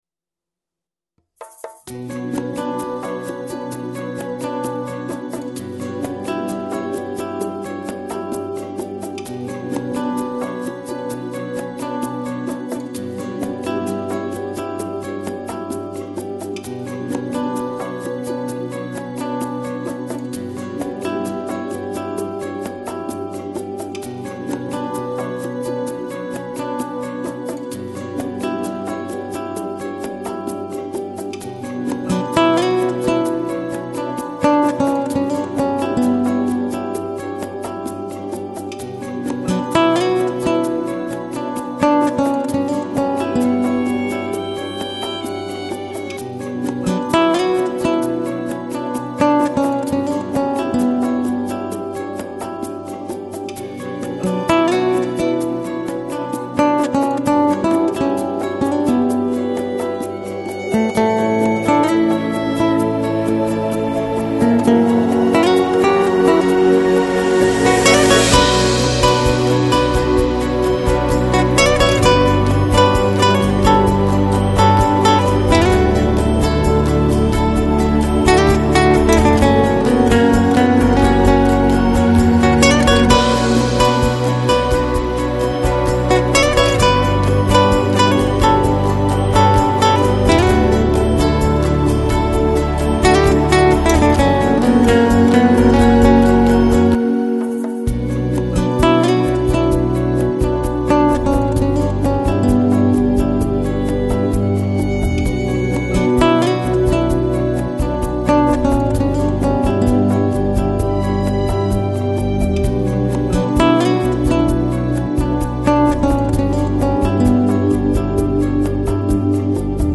Жанр: Downtempo, Lounge, Chill Out